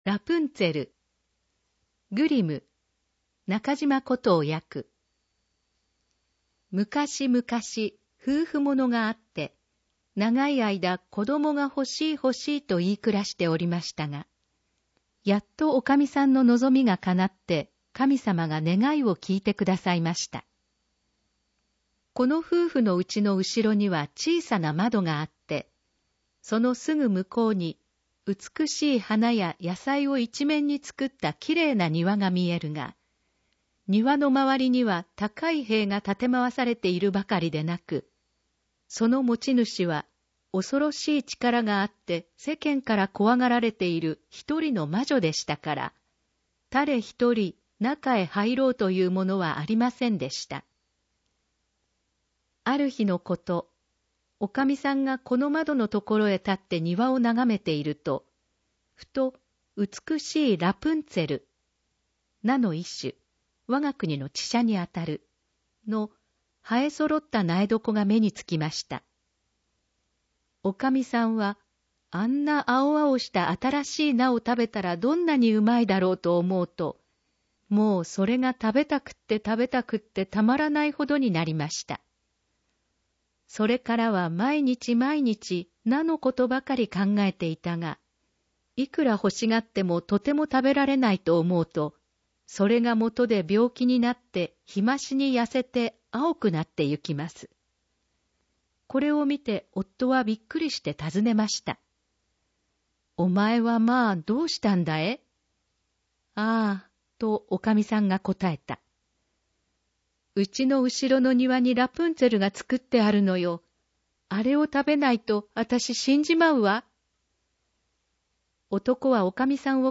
ぬり絵①（PDF・999KB） ぬり絵②（PDF・1342KB） 読み聞かせ お家でも楽しめるよう、音訳ボランティアさんによる読み聞かせをネット配信します！